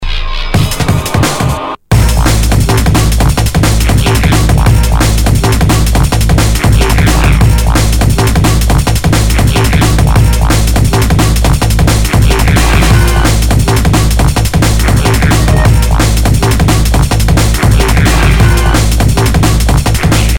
Drum'n'bass